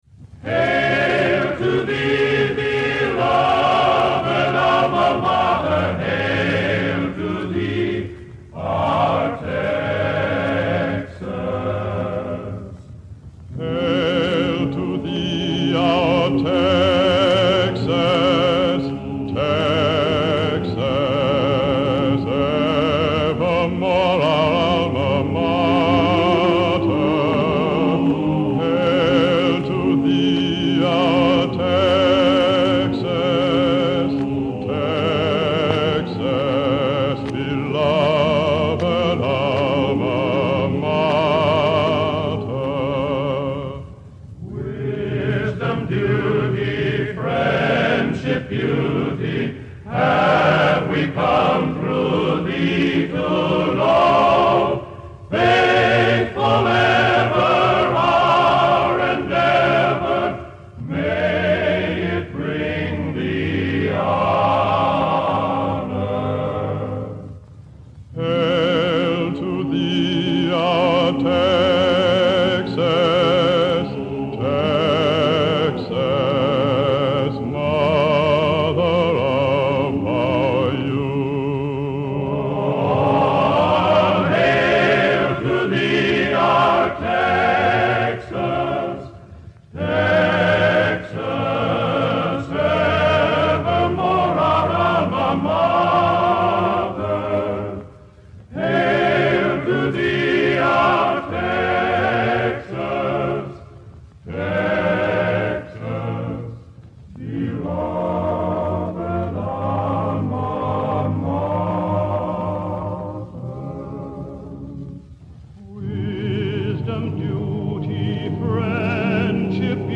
A University Hymn